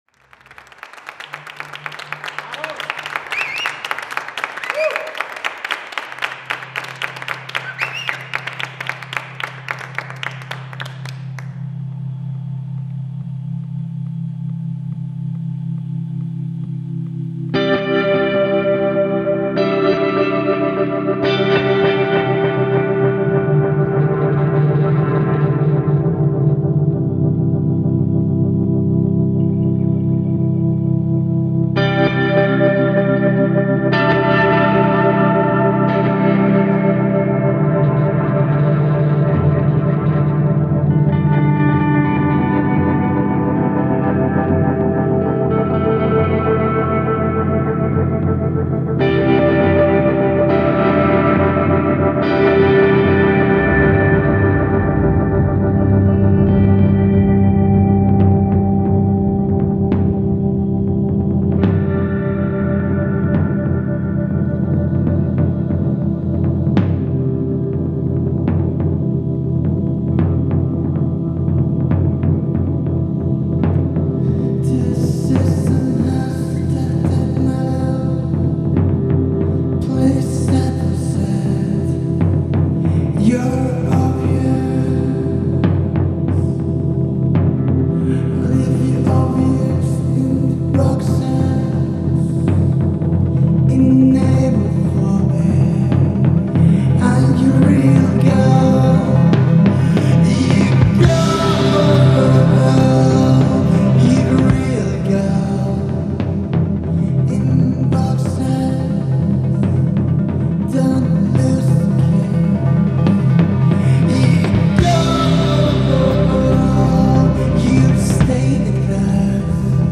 Indie/Alternative/Psych/Eletronica band
A dose of Indie/Alt/Electronic from Spain tonight.